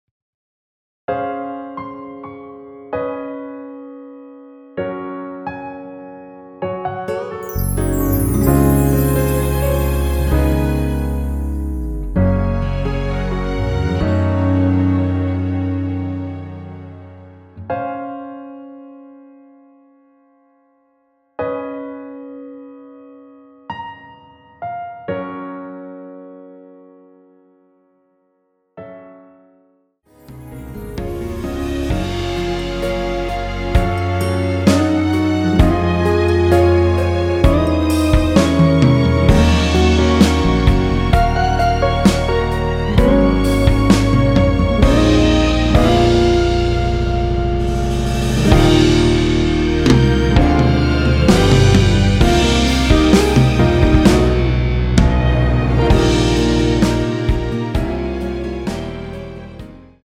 원키에서(-1)내린 MR입니다.(미리듣기 확인)
Db
앞부분30초, 뒷부분30초씩 편집해서 올려 드리고 있습니다.
중간에 음이 끈어지고 다시 나오는 이유는